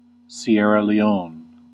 Ääntäminen
Ääntäminen US Haettu sana löytyi näillä lähdekielillä: englanti Käännös Erisnimet 1. Sierra Leone {f} Määritelmät Erisnimet Country in Western Africa.